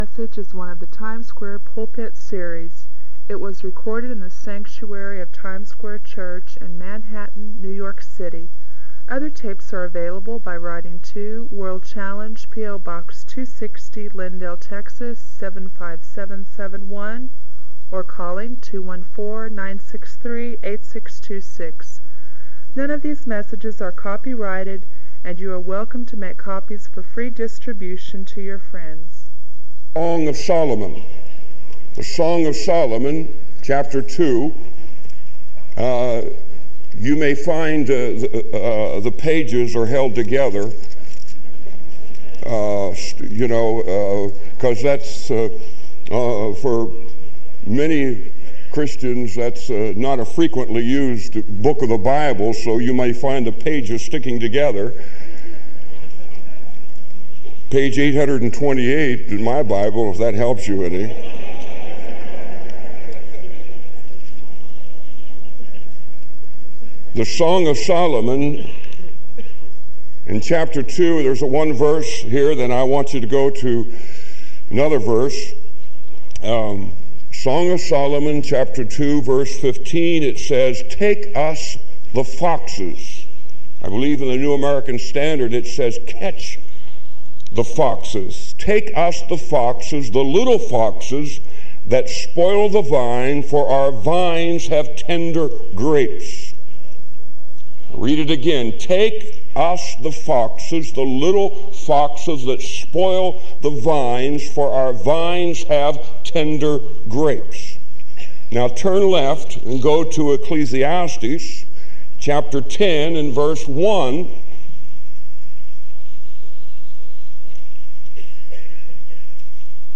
This sermon is a call to vigilance and wholehearted devotion in the Christian life.